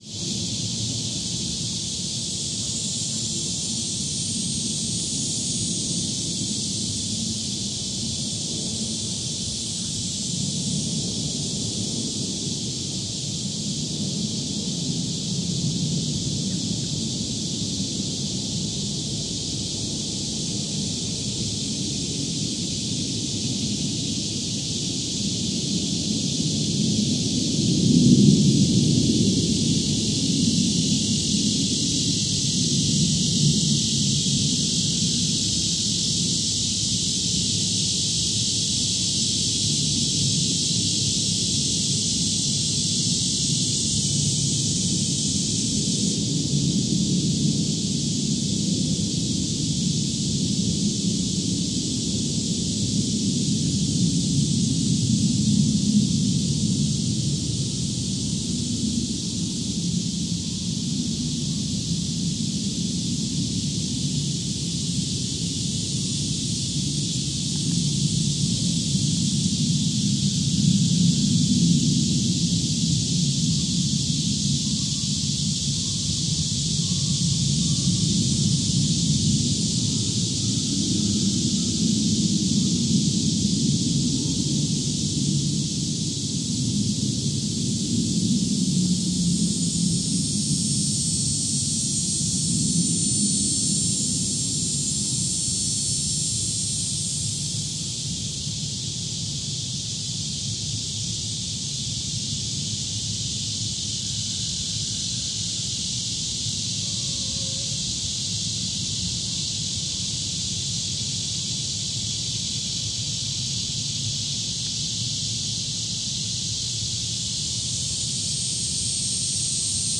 描述：在远足/骑车/慢跑的蚊子多的路段，你可以听到昆虫和鸟儿在早晨醒来。 此外，你还可以听到慢跑者经过，树枝断裂的声音，以及蚊子对麦克风的俯冲轰炸声。 这段录音是2007年8月21日上午在美国弗吉尼亚州弗吉尼亚海滩的First Landing州立公园用Zoom H4录制的。 轻微的后期制作工作在Peak.
Tag: 现场录音 请先登陆状态园 昆虫 慢跑 沼泽